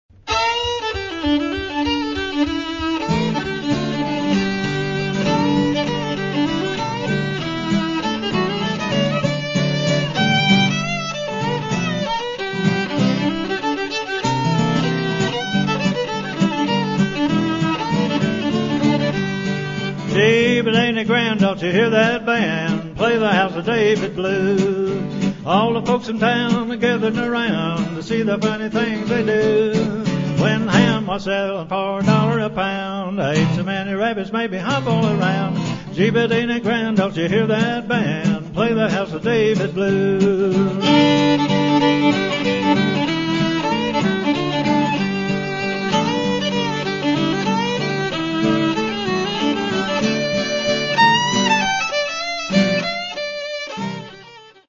Old-Time Songs & Longbow Fiddle
fiddle and lead vocals
guitar and harmony vocals.